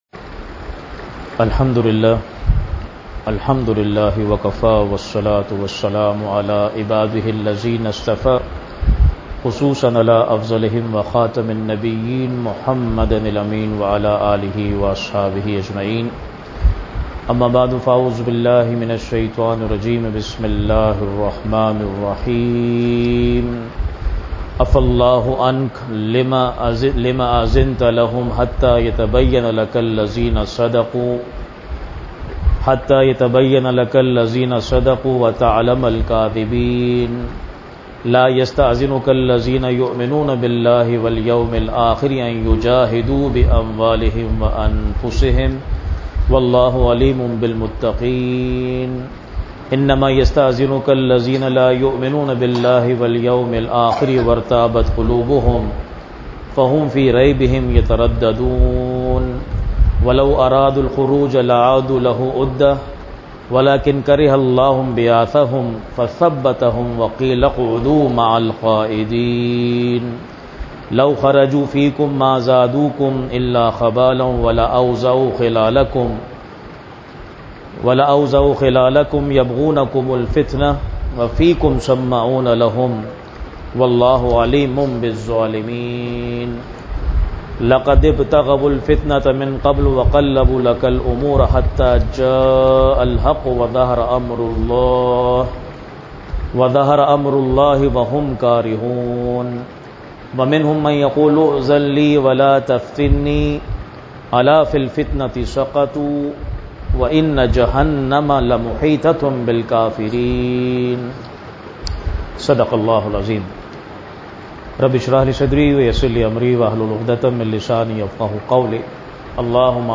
Weekly Dars-e-Quran